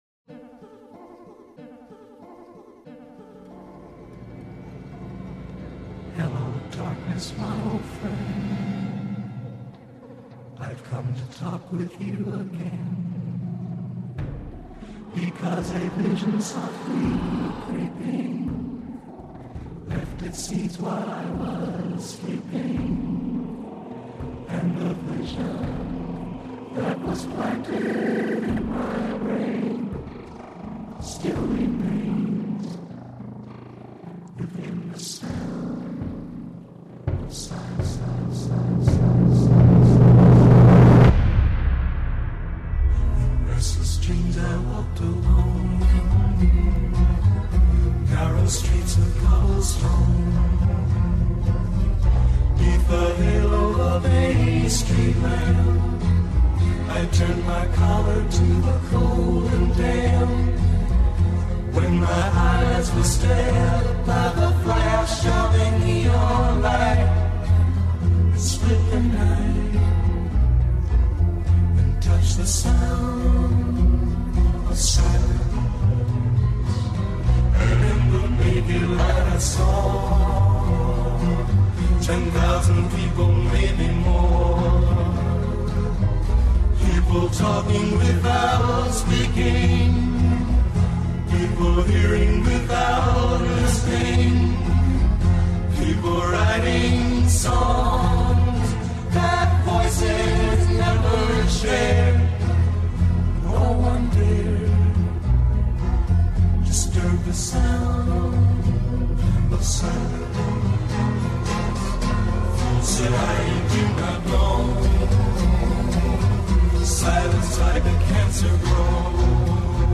you do, it's a roughish mix) that they will be so.. well not exactly pleased or